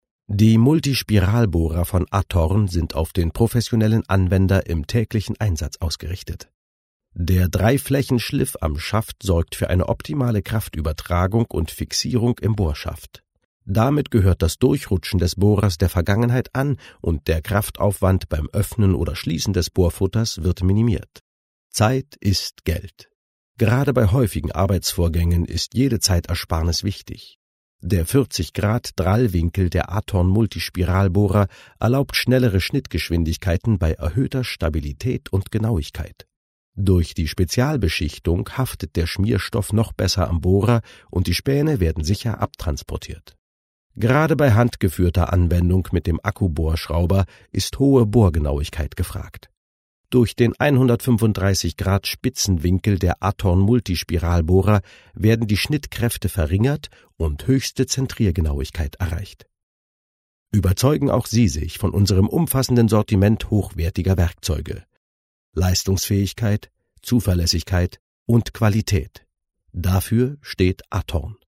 warme Stimme, Stimmlage Bariton, sehr flexibel
Sprechprobe: Industrie (Muttersprache):